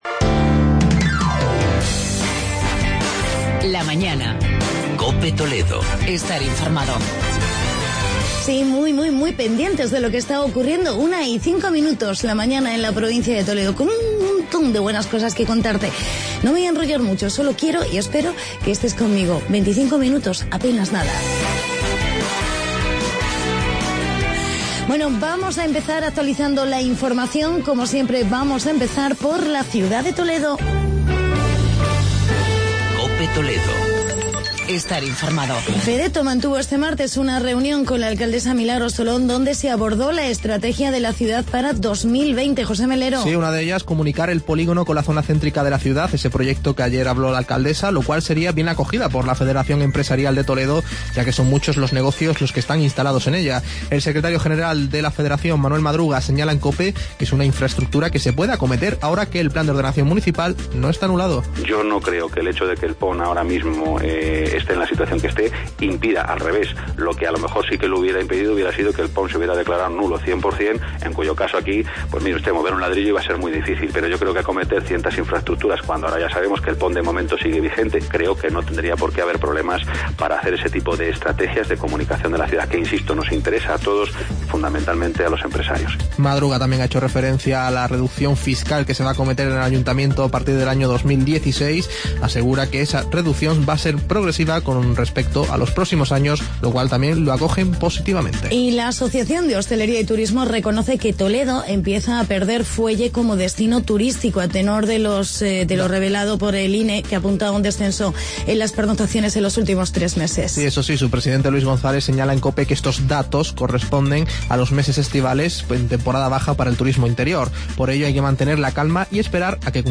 Entrevista con el concejal de Economía y Hacienda